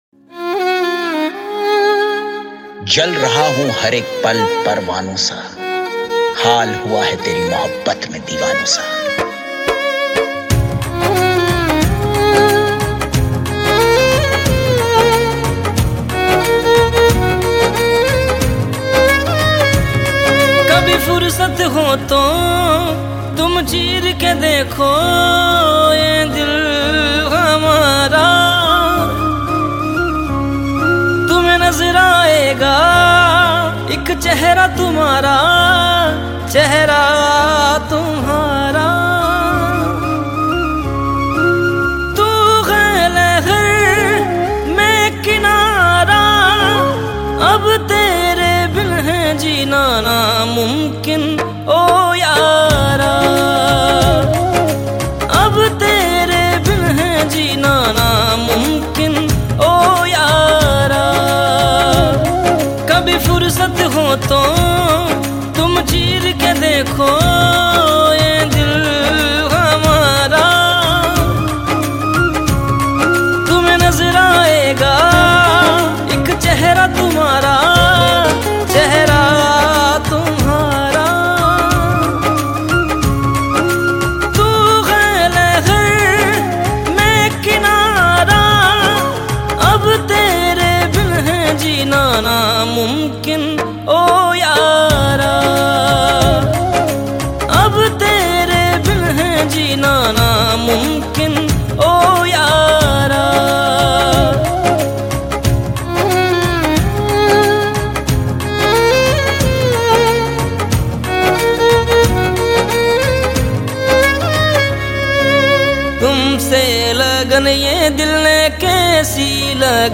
Hindi Pop Album Songs 2022